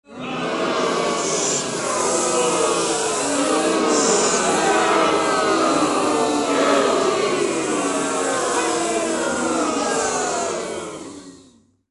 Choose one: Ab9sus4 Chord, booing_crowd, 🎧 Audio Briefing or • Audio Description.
booing_crowd